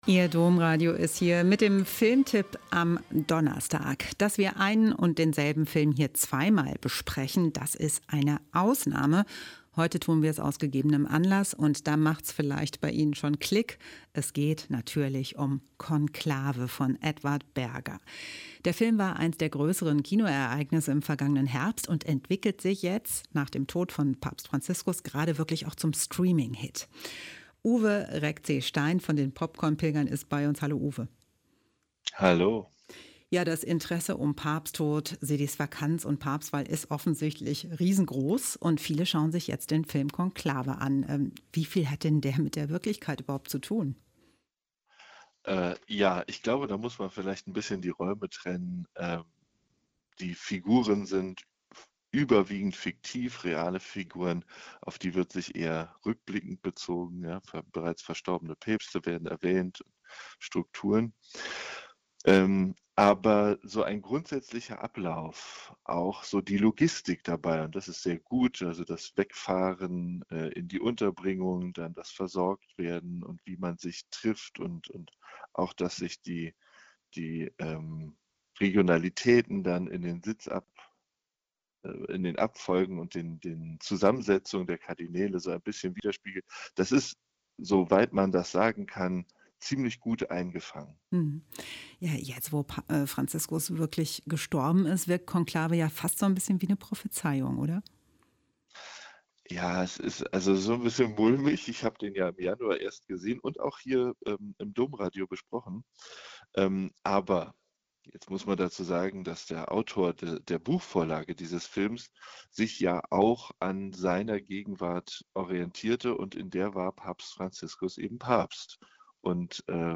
Filmtipp mit den Popcorn-Pilgern: "Konklave"